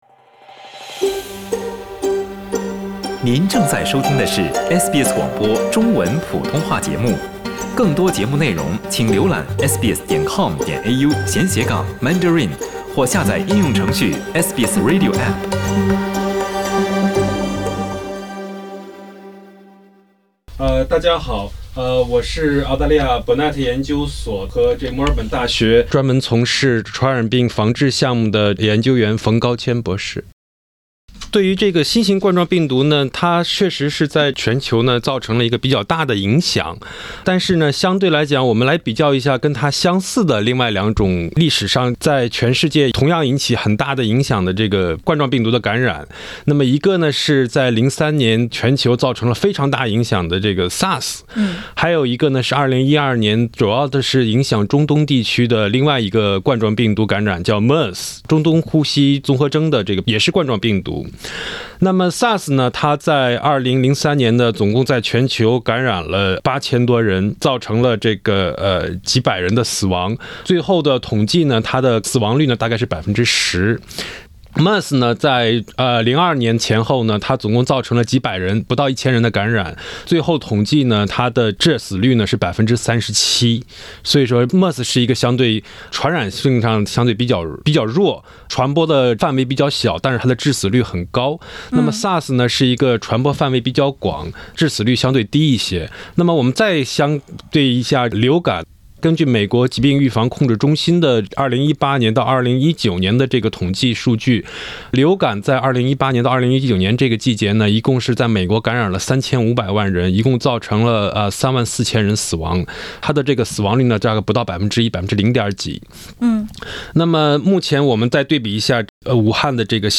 传染病专家专访：新型冠状病毒，没你想的那么“毒”